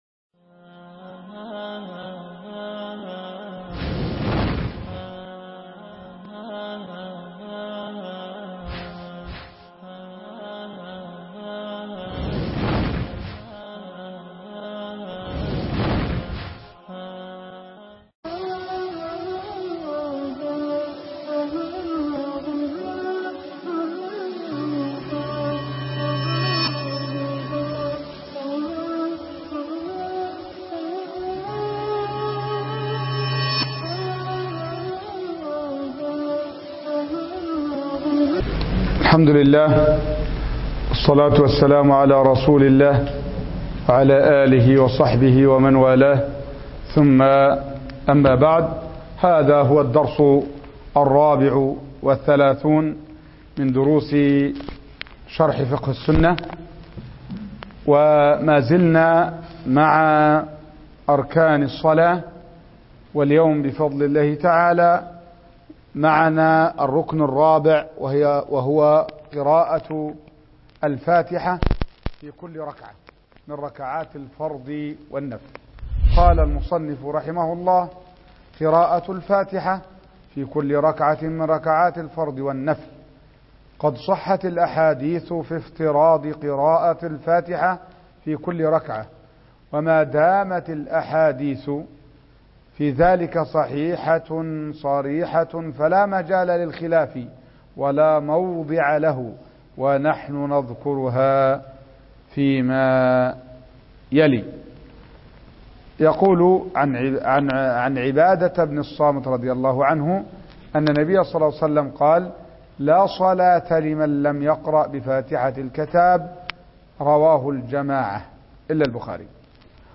شرح كتاب فقه السنة الدرس 34